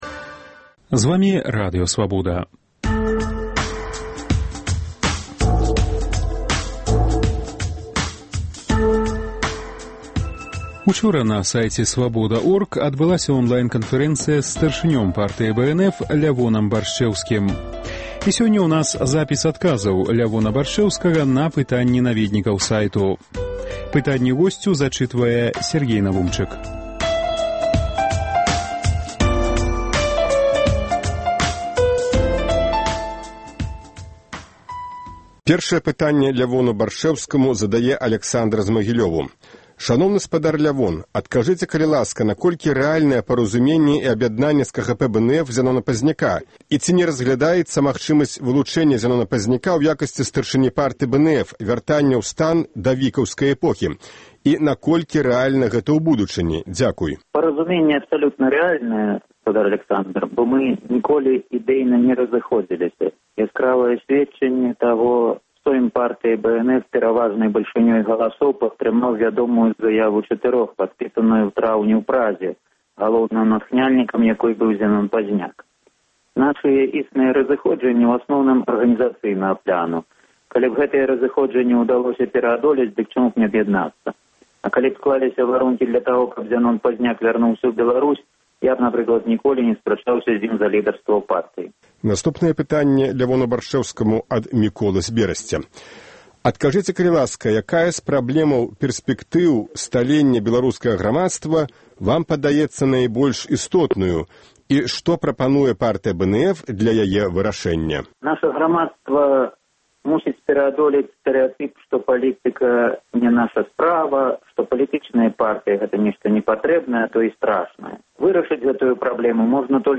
Радыёварыянт онлайн-канфэрэнцыі са старшынём Партыі БНФ Лявонам Баршчэўскім.